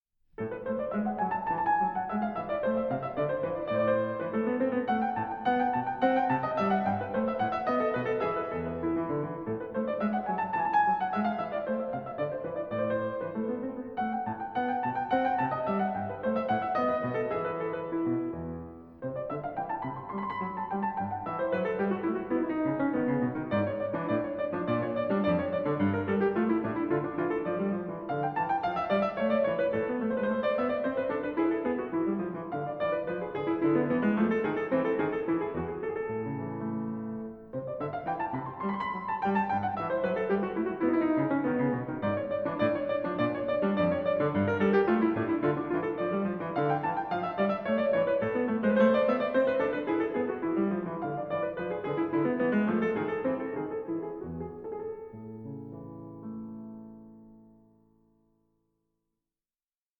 Allegro 0:48
pianist